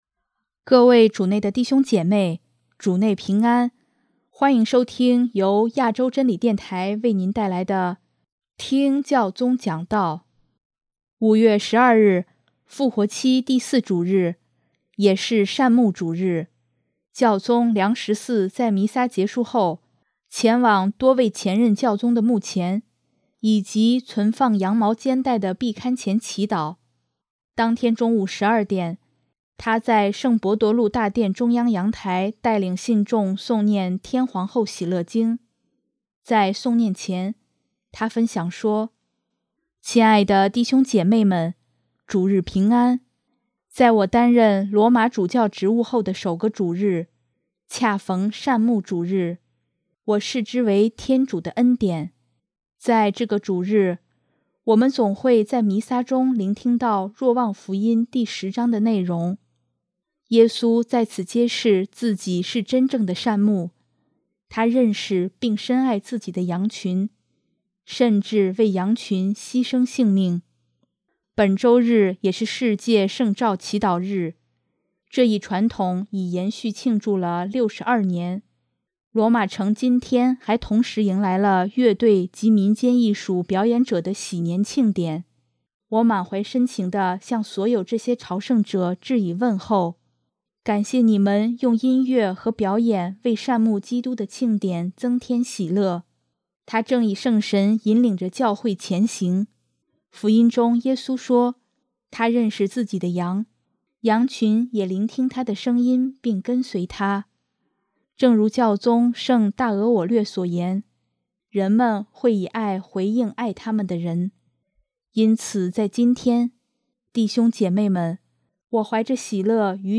【听教宗讲道】|“人们会以爱回应爱他们的人”
当天中午12点，他在圣伯多禄大殿中央阳台带领信众诵念《天皇后喜乐经》，在诵念前，他分享说：